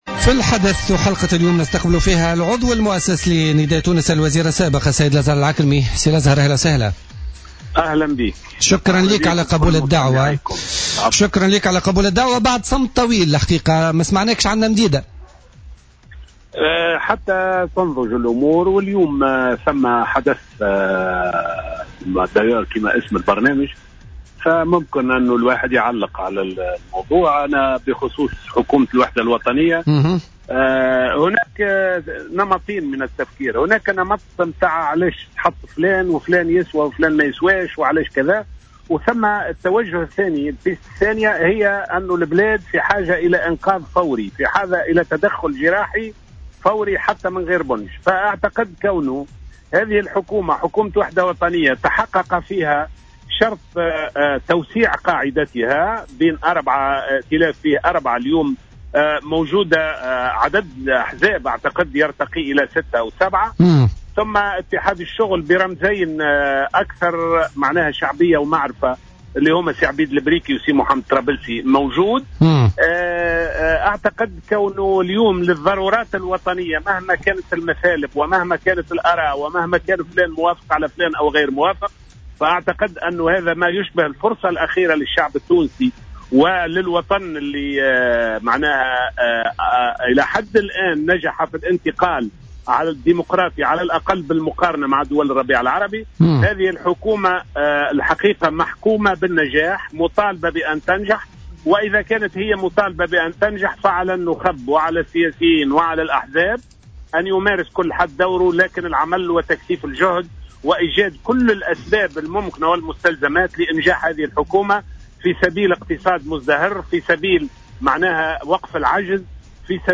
أكد العضو المؤسس لحركة نداء تونس والوزير السابق في حكومة الحبيب الصيد لزهر العكرمي في مداخلة له في برنامج الحدث على الجوهرة "اف ام" اليوم الاثنين أن البلاد في حاجة الى انقاذ فوري وتدخل جراحي عاجل على حد قوله.